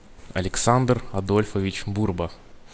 Aleksander Adolfovich Burba (Russian: Алекса́ндр Адо́льфович Бу́рба, IPA: [ɐlʲɪkˈsandr ɐˈdolʲfəvʲɪdʑ ˈburbə]